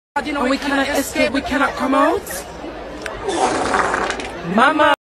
Wet Fart Sound Effect Meme Sound Button - Free Download & Play